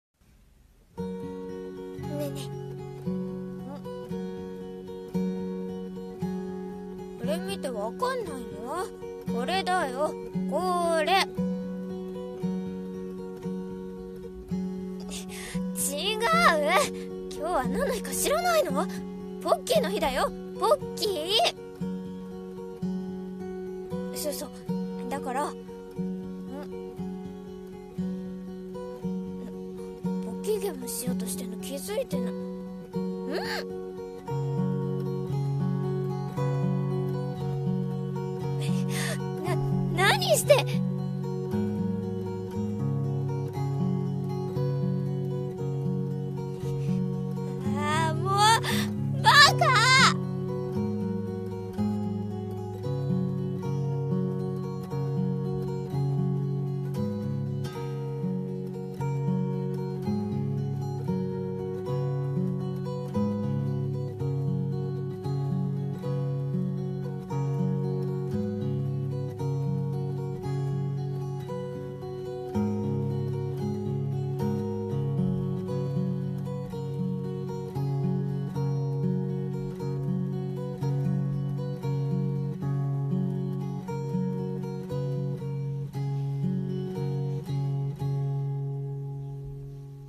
【声劇】ポッキーゲーム。